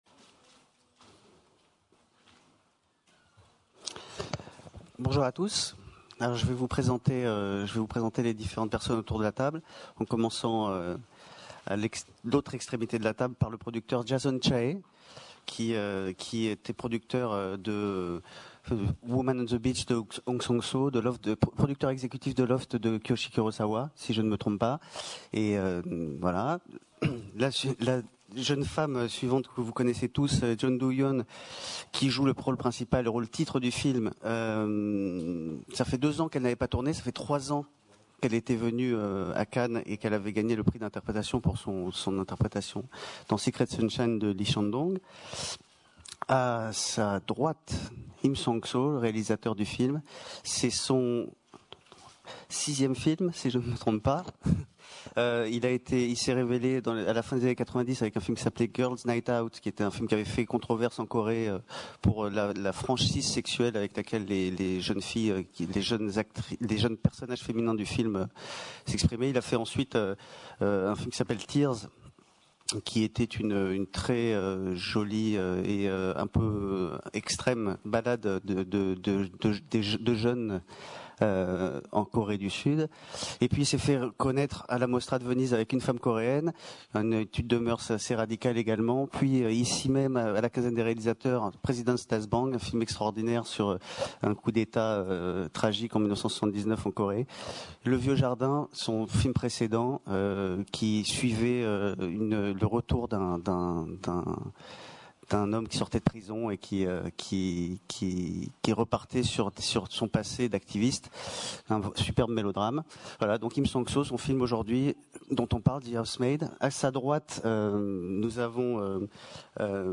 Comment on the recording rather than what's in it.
Ascolta la conferenza stampa di presentazione del film al Festival di Cannes.